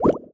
etfx_shoot_bubble2.wav